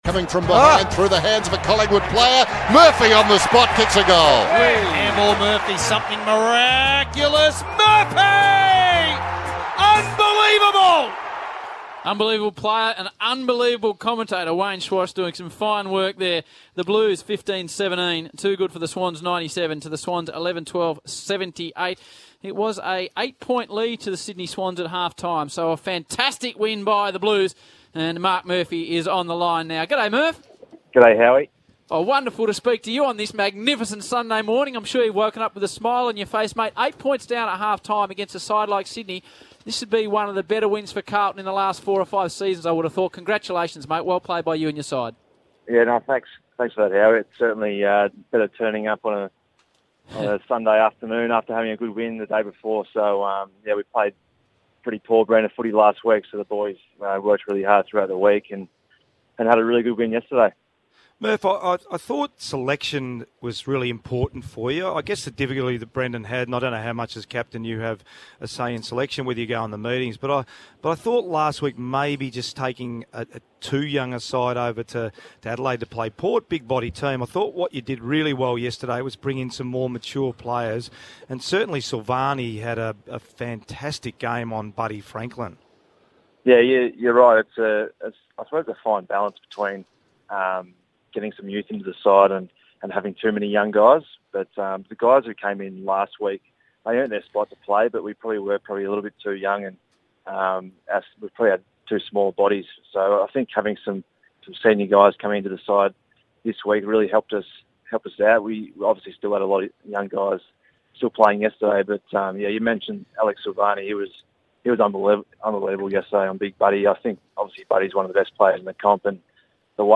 Carlton captain Marc Murphy speaks to Triple M after the Blues' 19-point victory over the Swans.